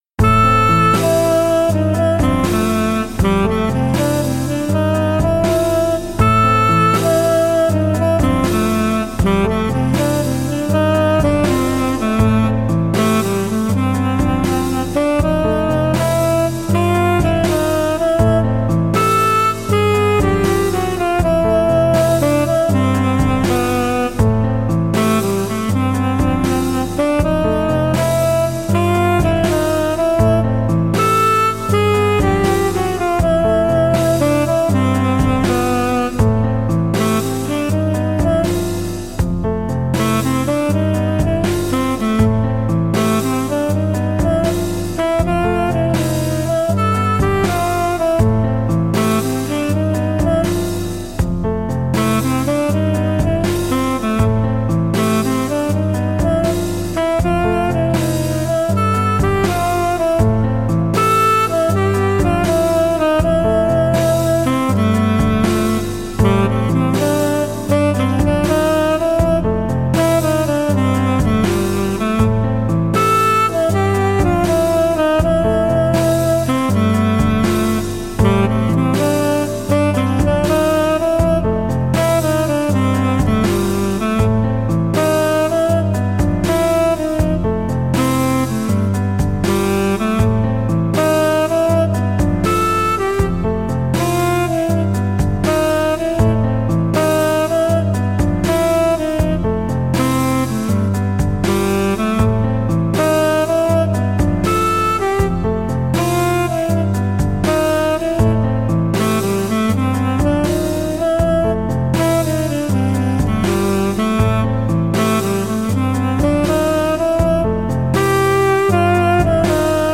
セクシーな感じの曲です。【BPM80】